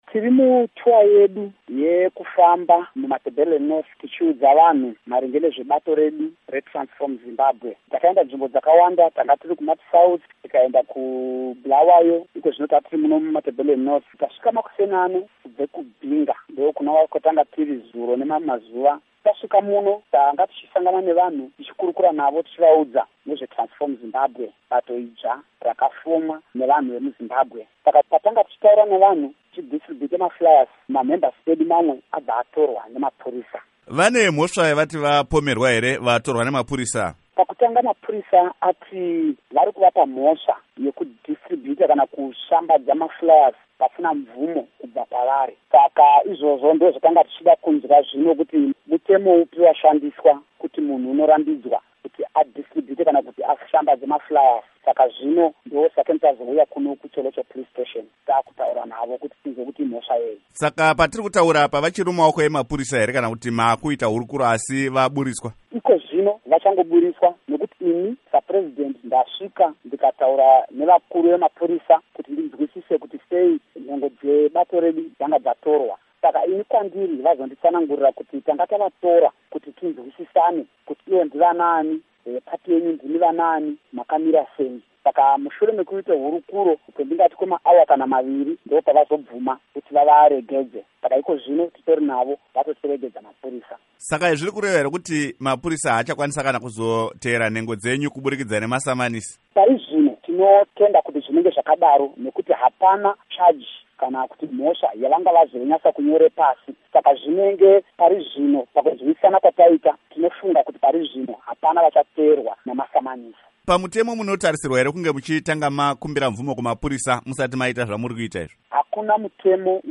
Hurukuro naVaJacob Satiya Ngarivhume